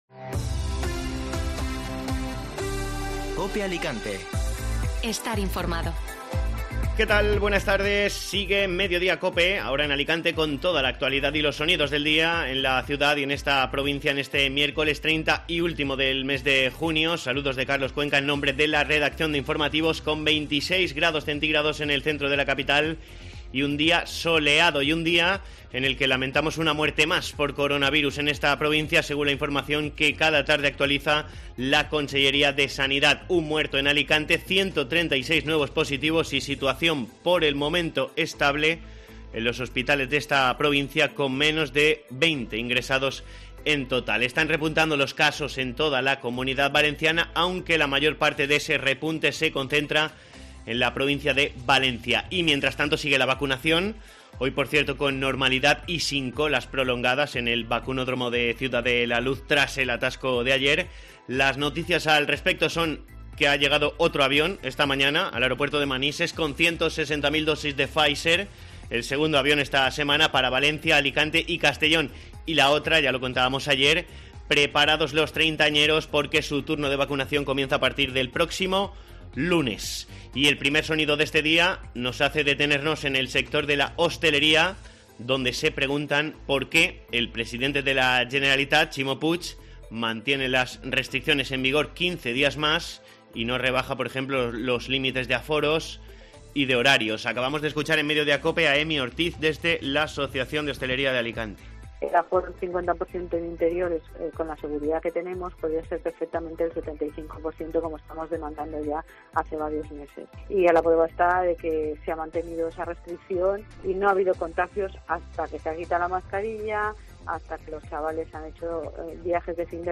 Informativo Mediodía COPE (Miércoles 30 de junio)